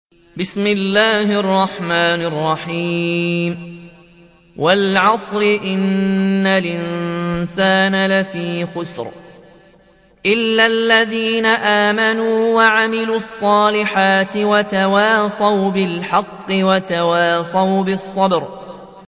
تحميل : 103. سورة العصر / القارئ ياسين الجزائري / القرآن الكريم / موقع يا حسين